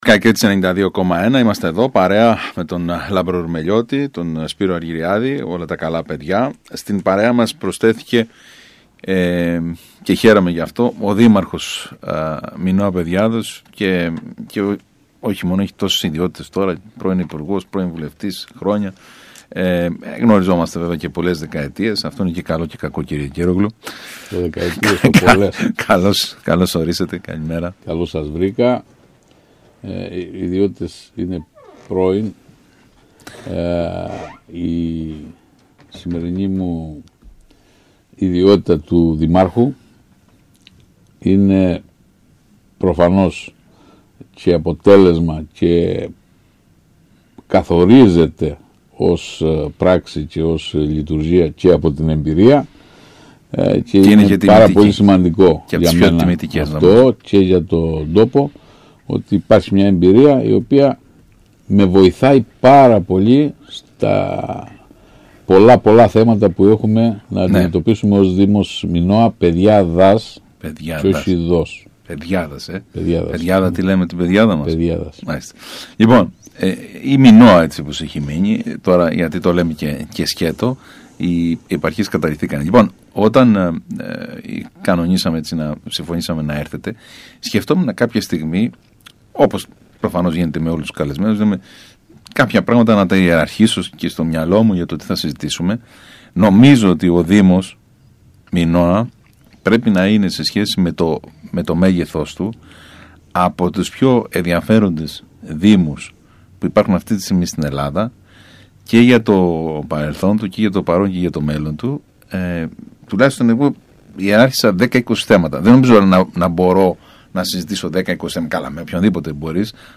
Ένας από τους δήμους που πλήττεται περισσότερο είναι ο δήμος Μινώα» τόνισε μιλώντας στον ΣΚΑΪ Κρήτης ο Βασίλης Κεγκέρογλου.